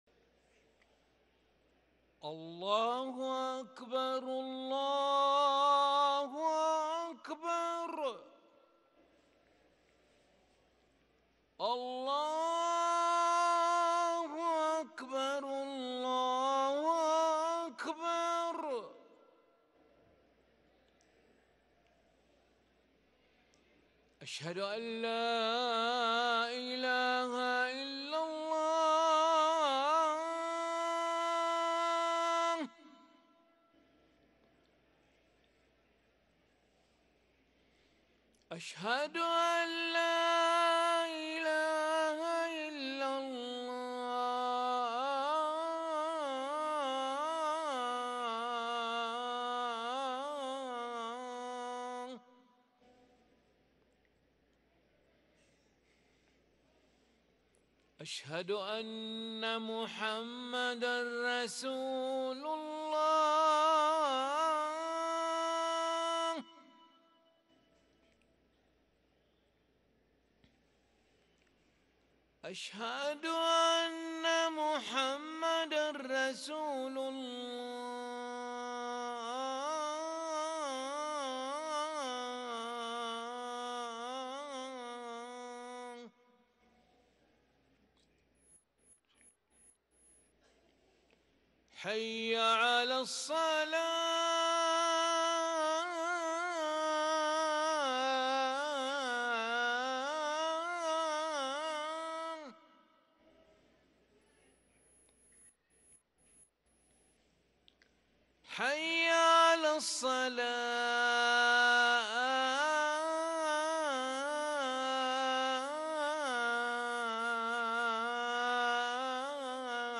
أذان العشاء للمؤذن علي ملا الأحد 12 ربيع الآخر 1444هـ > ١٤٤٤ 🕋 > ركن الأذان 🕋 > المزيد - تلاوات الحرمين